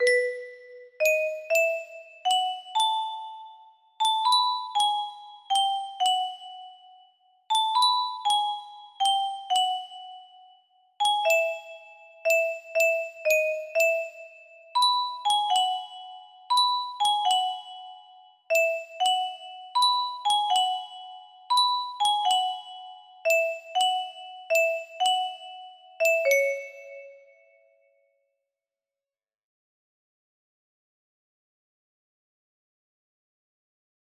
Luna music box melody